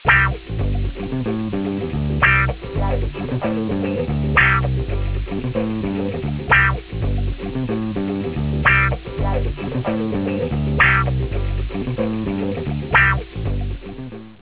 think music